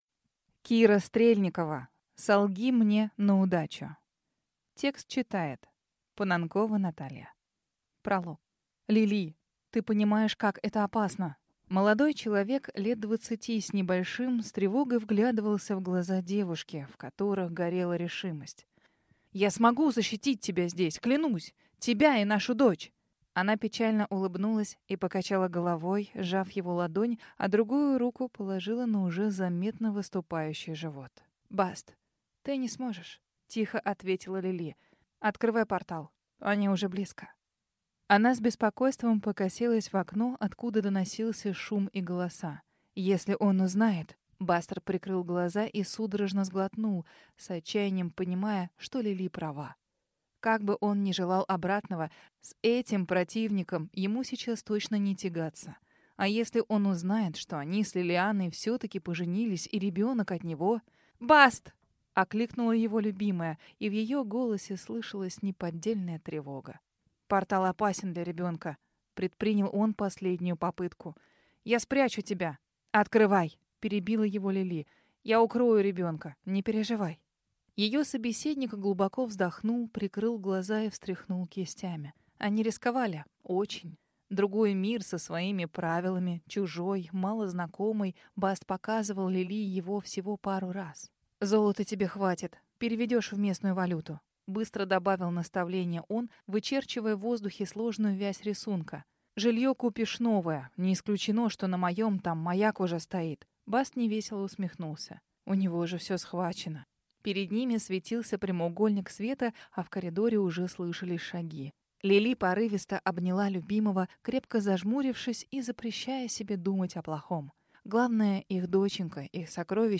Аудиокнига Солги мне на удачу | Библиотека аудиокниг
Прослушать и бесплатно скачать фрагмент аудиокниги